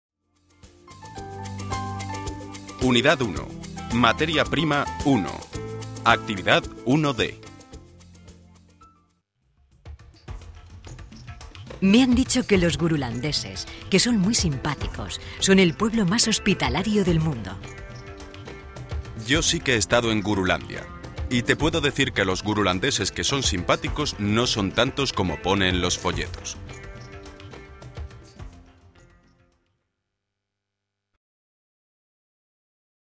Muchos de estos documentos de audio son materiales auténticos procedentes de noticias difundidas en la radio y la televisión...